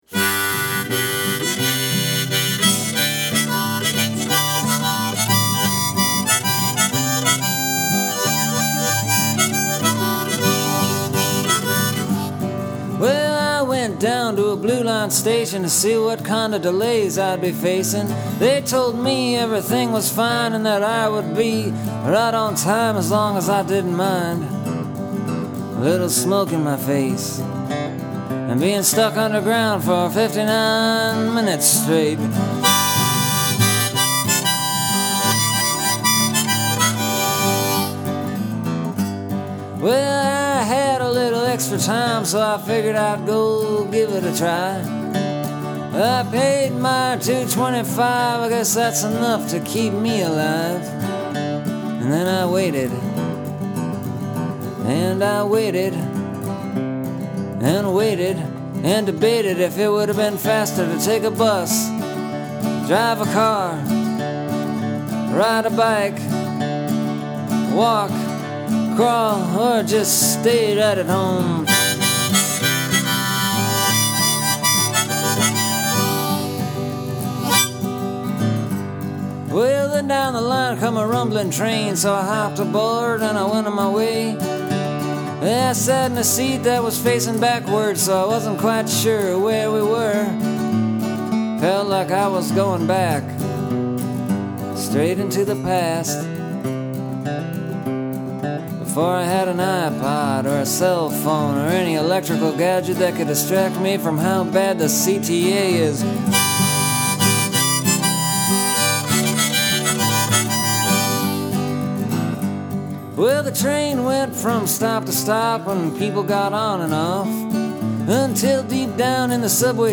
It’s a funny song about a sad thing.